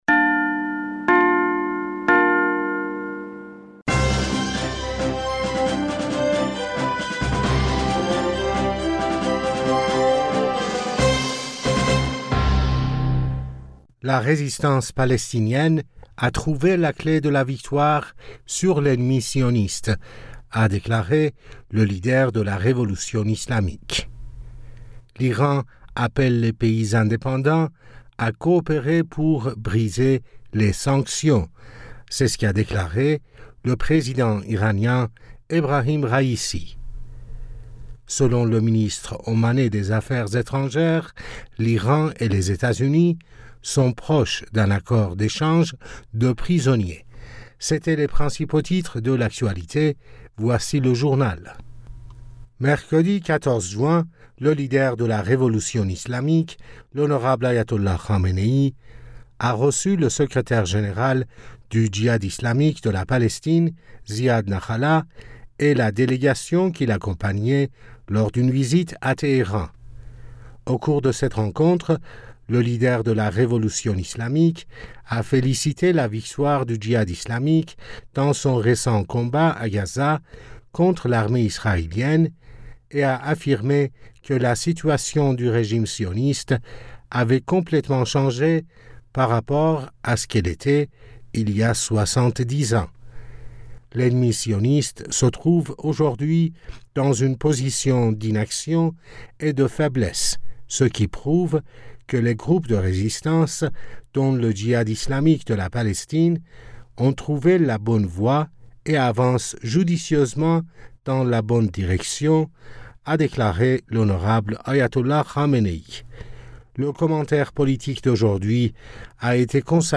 Bulletin d'information du 15 Juin 2023